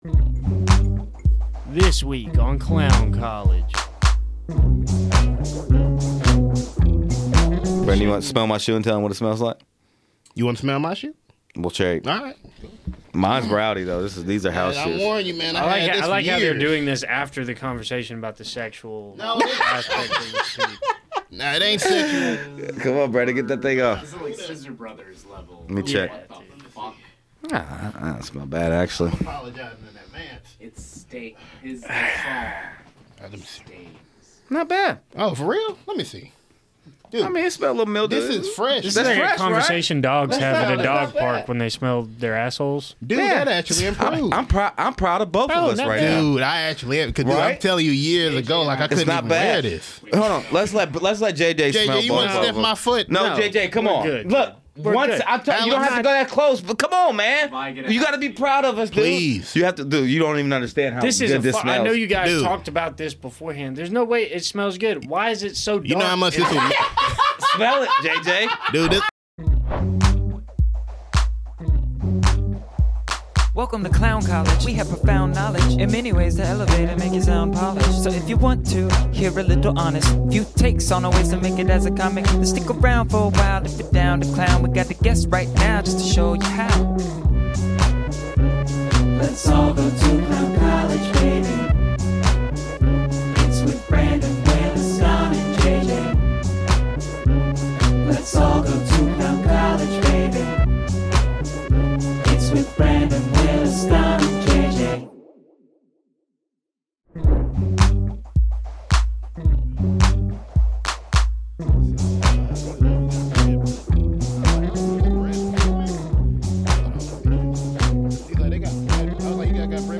on the 1's and 2's. Get ready to hear about comedy in a way I guarantee you never have before. Reactions, interviews, skits, you name it.